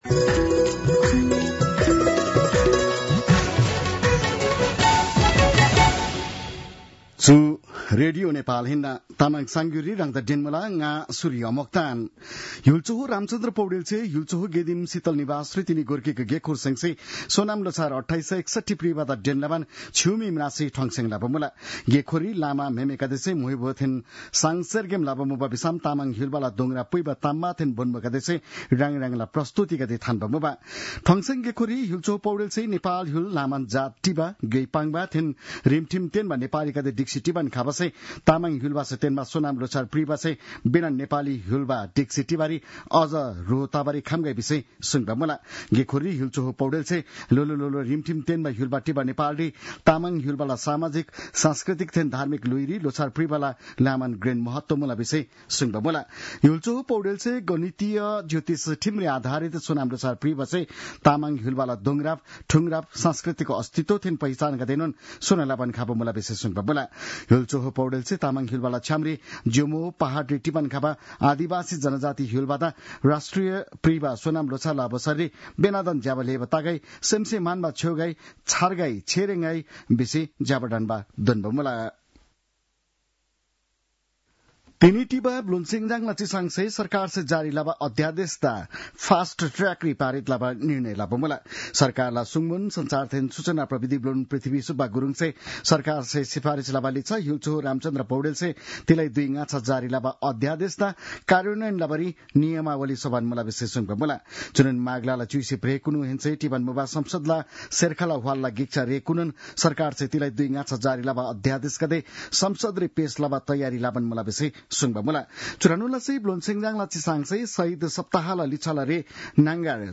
तामाङ भाषाको समाचार : १६ माघ , २०८१